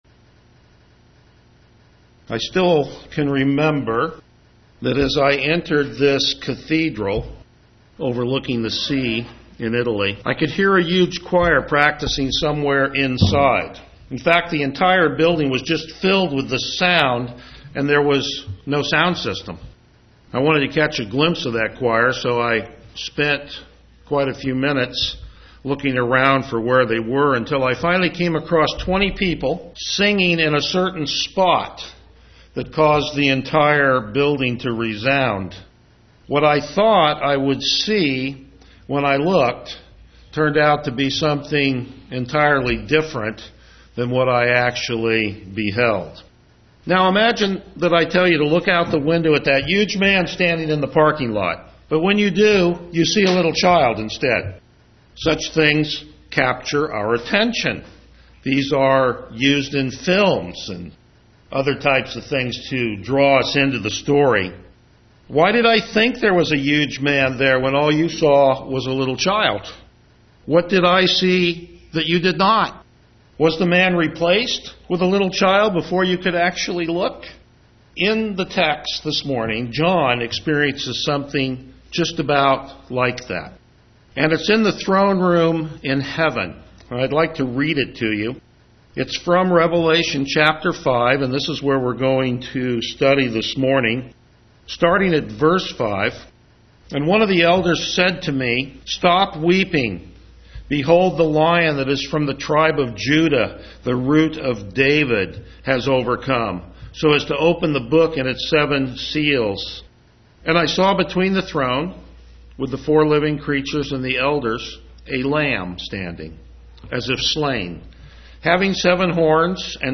October 28, 2012 Behold the Lamb of God Series: The Gospel of John Passage: John 1:29-39 Service Type: Morning Worship Download Files Notes Topics: Verse By Verse Exposition « The Word Revealed Follow Me!